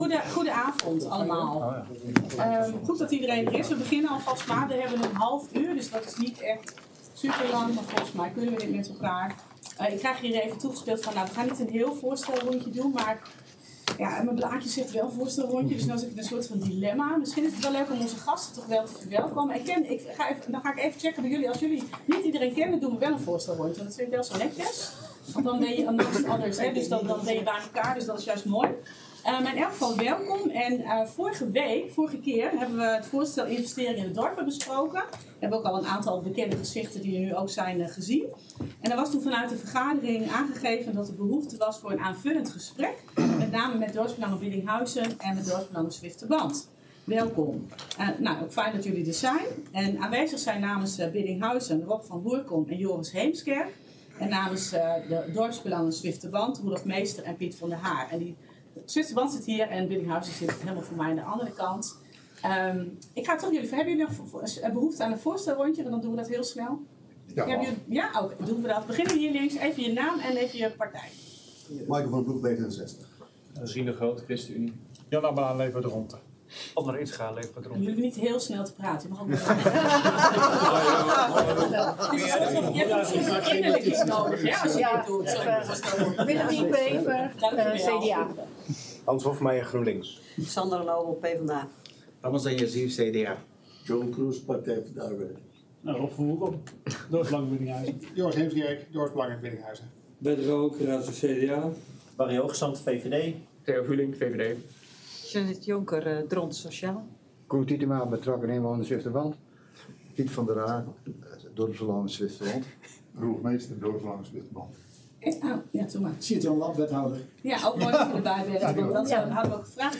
Beeldvormende vergadering 15 februari 2024 20:30:00, Gemeente Dronten
Locatie: Raadzaal
Voor de behandeling is wethouder Lap uitgenodigd.